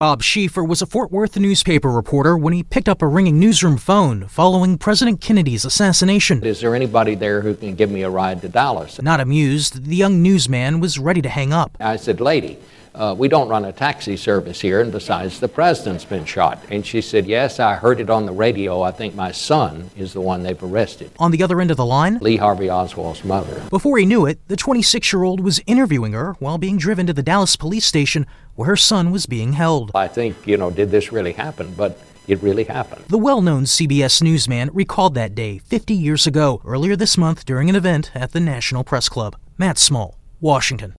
A long-time journalist recalls his scoop from November 22nd, 1963.